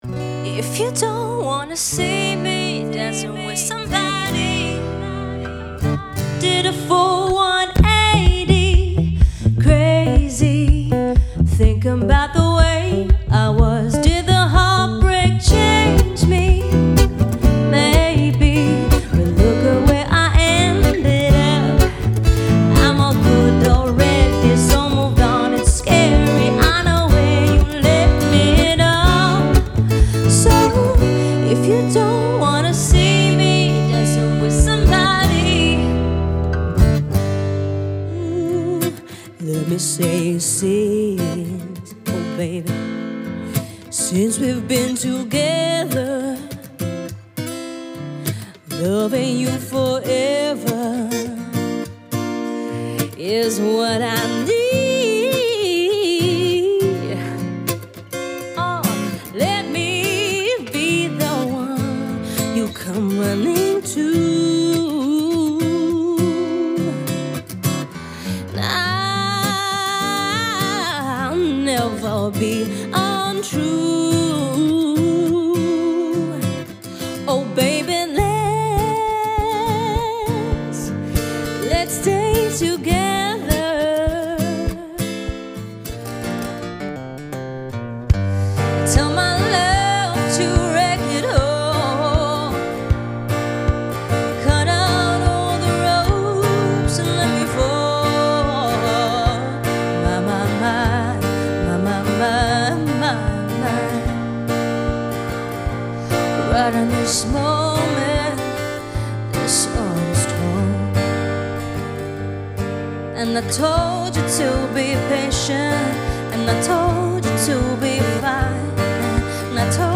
Dual Vocals | Keyboard | Guitar
everything from Pop, RnB, Funk, Soul, Jazz
guitar